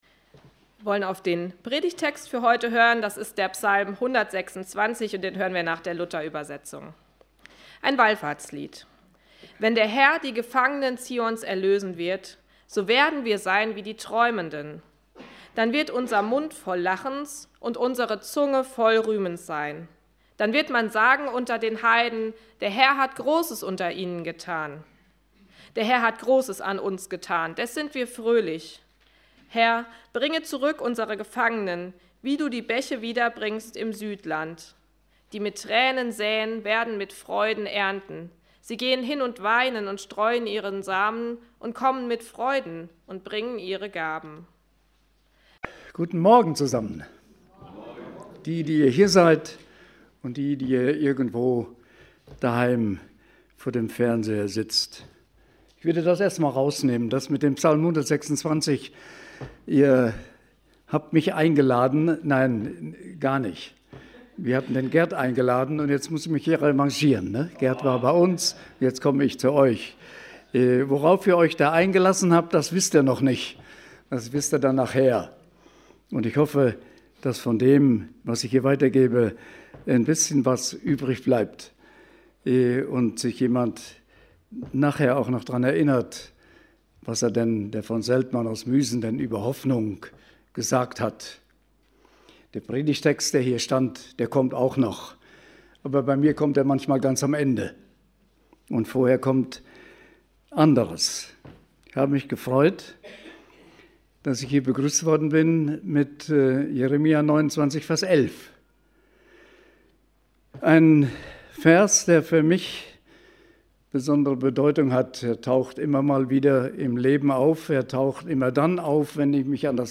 Hoffnung ~ Predigt Podcast Evangelische Gemeinschaft Kredenbach Podcast
Predigten der Evangelischen Gemeinschaft Kredenbach.